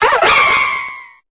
Cri de Viridium dans Pokémon Épée et Bouclier.